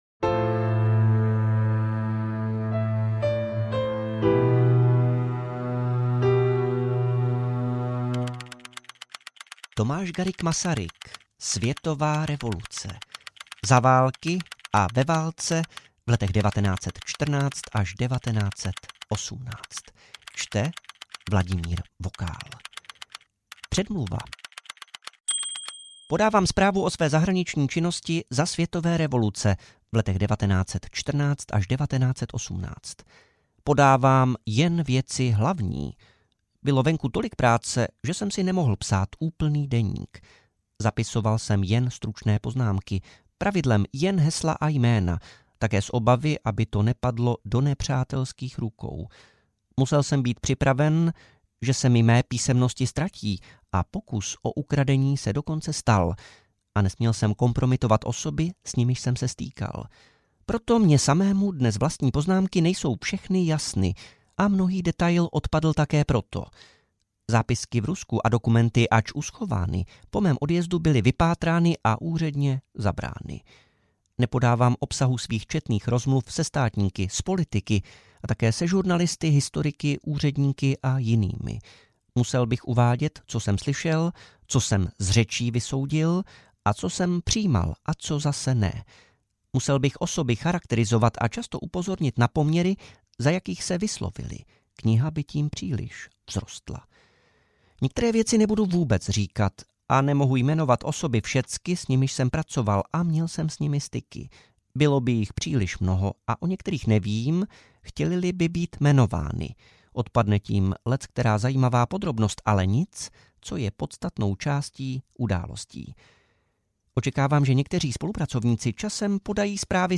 Světová revoluce - části 1 a 2 audiokniha
Ukázka z knihy